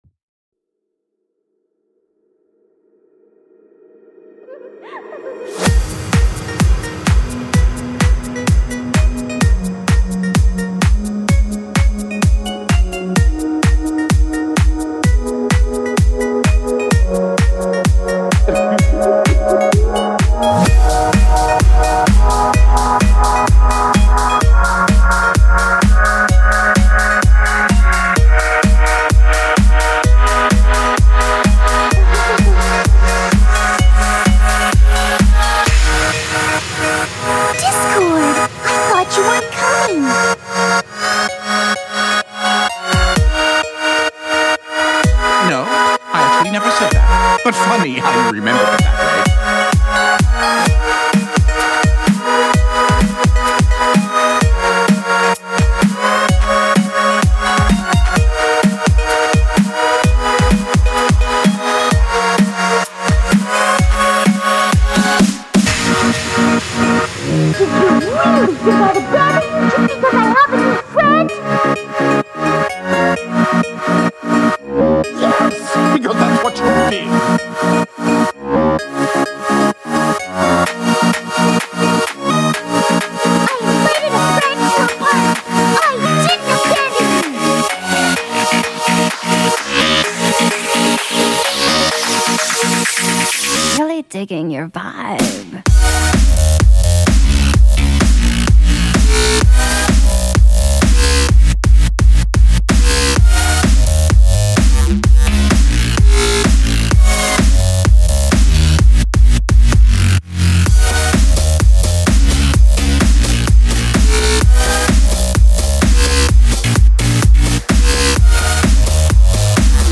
I had more of a heavier style of inspiration